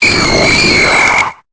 Cri de Fantominus dans Pokémon Épée et Bouclier.